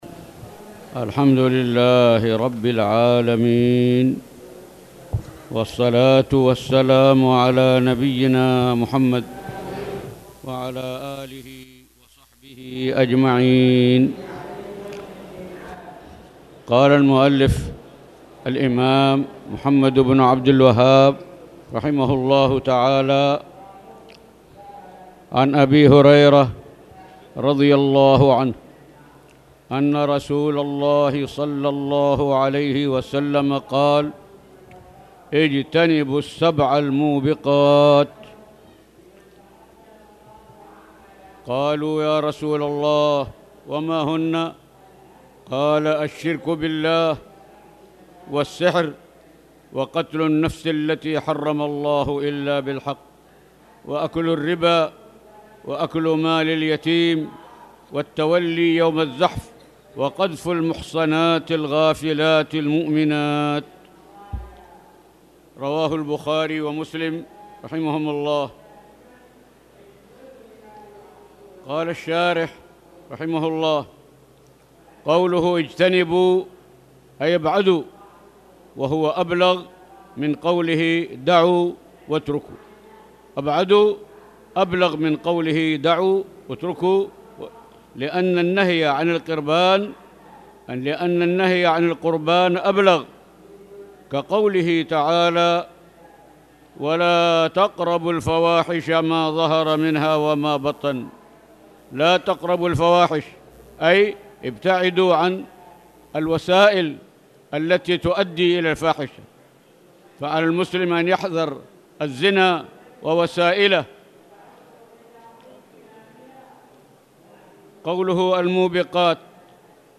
تاريخ النشر ١٦ ذو الحجة ١٤٣٧ هـ المكان: المسجد الحرام الشيخ